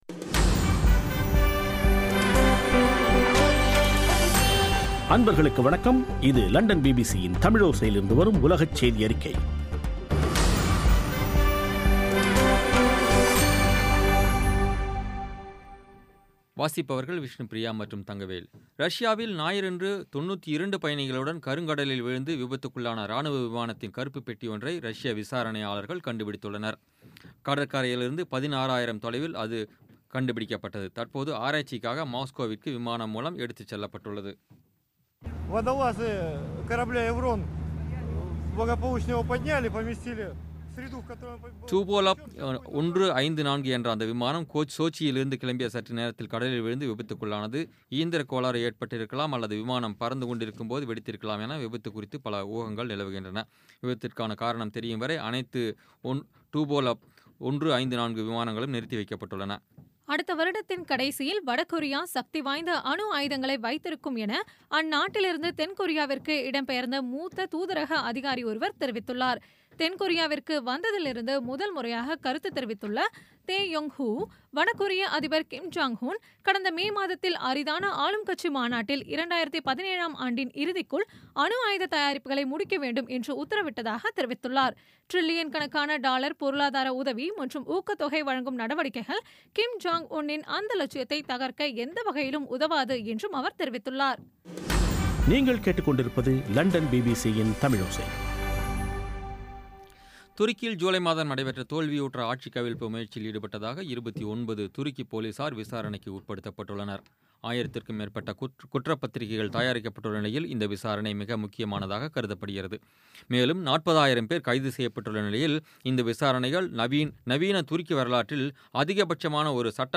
பிபிசி தமிழோசை செய்தியறிக்கை (27/12/2016)